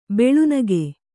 ♪ beḷu nage